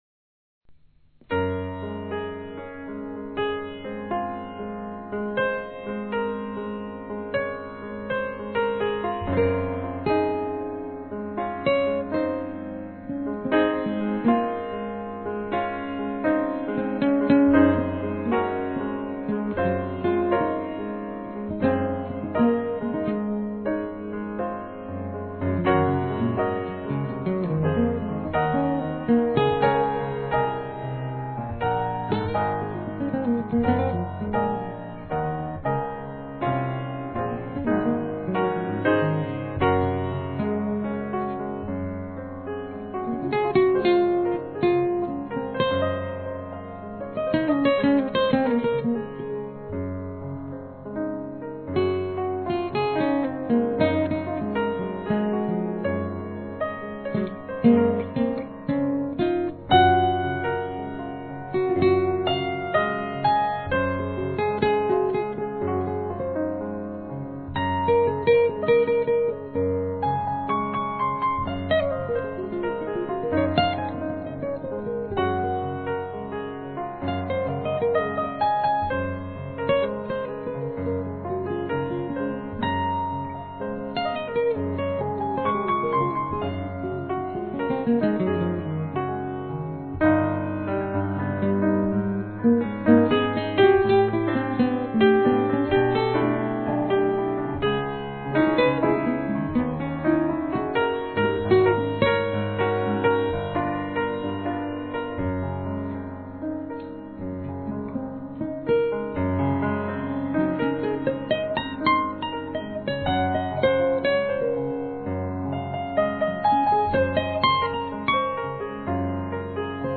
trumpet & flugelhorn
tuba
tenor sax, american voice
guitar
piano
bass
drums
clarinet or tenor sax
mandolin, english horn
violin
accordion
italian voice